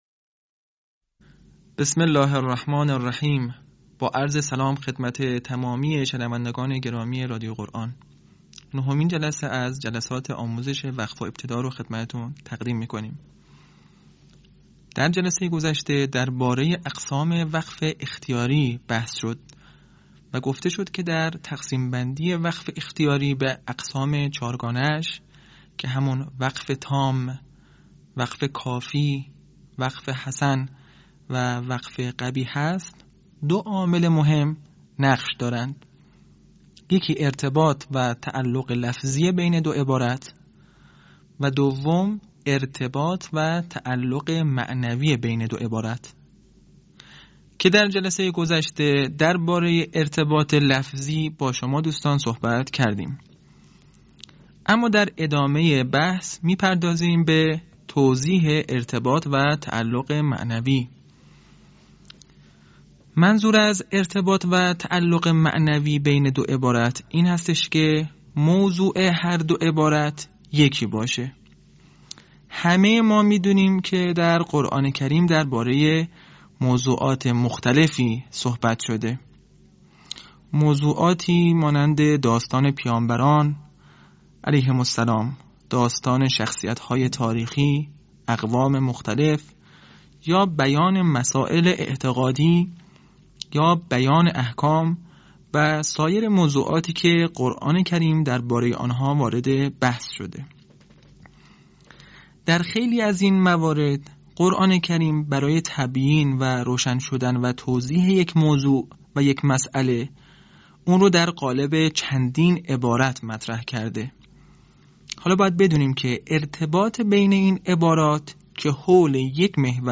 به همین منظور مجموعه آموزشی شنیداری (صوتی) قرآنی را گردآوری و برای علاقه‌مندان بازنشر می‌کند.
آموزش قرآن